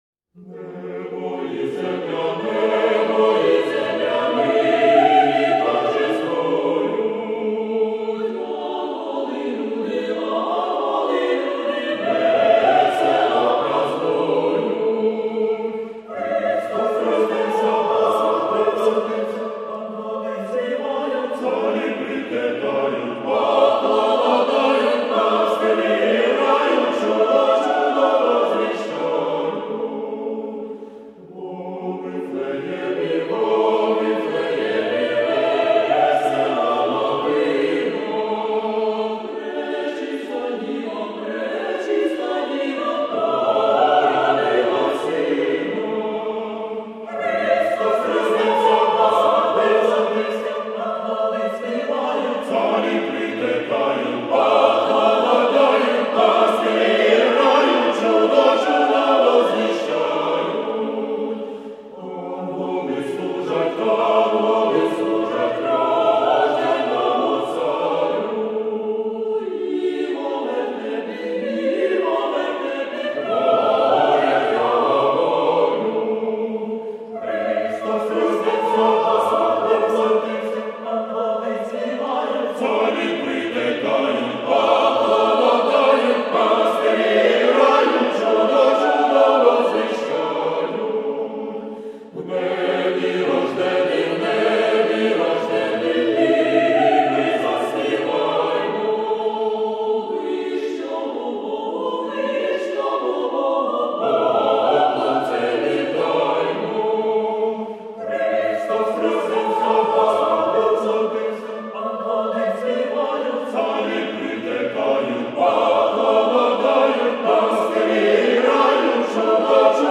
SATB (4 voices mixed) ; Choral score with piano for rehearsal only.
Carol.
Orthodox song.
Genre-Style-Form: Carol ; Sacred ; Orthodox song Mood of the piece: festive ; joyous
Tonality: A flat major